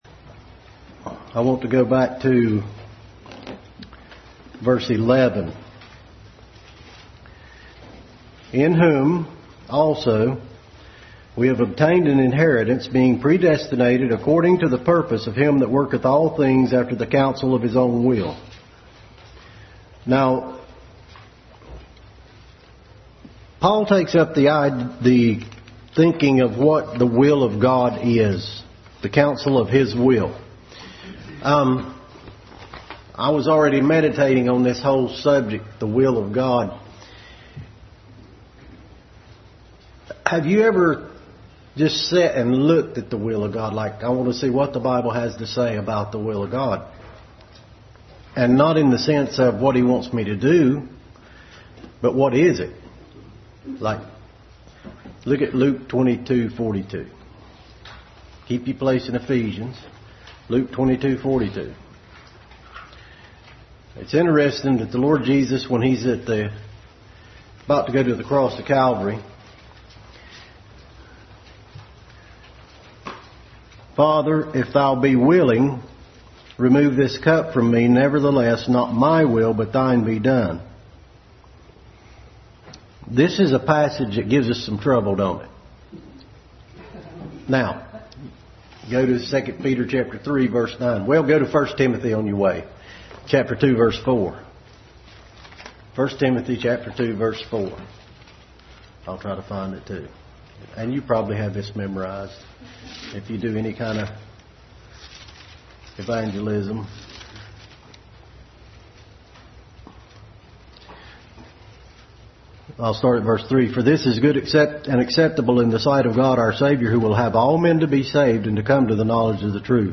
Adult Sunday School continued study in Ephesians.